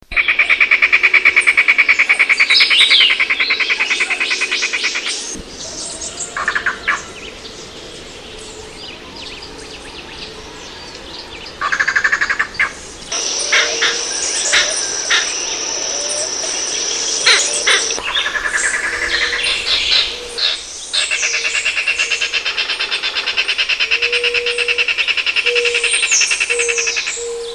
• Puerto Rican Lizard-Cuckoo (Coccyzus vieilloti)
VOICE: The song is a rapid series of "caw" notes, commonly described as "cow cow, kuk krrk"; also makes a variety of low-pitched calls.
HABITAT: Thickets, forests, and shade coffee plantations throughout the island.
lizard_cuckoo.mp3